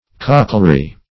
Search Result for " cochleary" : The Collaborative International Dictionary of English v.0.48: Cochleary \Coch"le*a*ry\, a. [L. cochlearum penfor snails (meaning formerly given, snail shell).